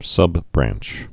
(sŭbbrănch)